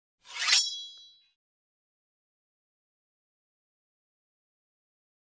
Sword Shing 2; Sword Edges Run Against Each Other.